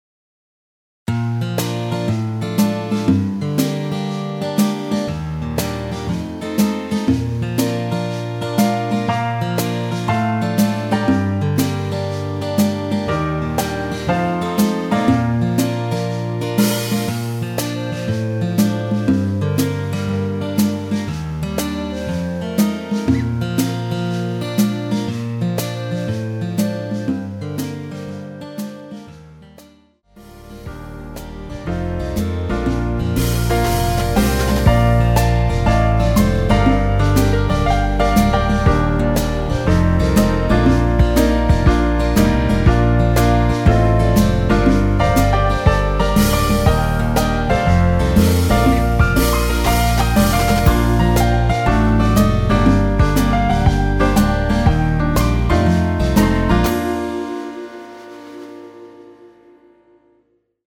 엔딩이 페이드 아웃에 너무 길어서 4마디로 엔딩을 만들었습니다.(미리듣기 참조)
원키에서(-2)내린 멜로디 포함된 MR 입니다.
Bb
앞부분30초, 뒷부분30초씩 편집해서 올려 드리고 있습니다.
중간에 음이 끈어지고 다시 나오는 이유는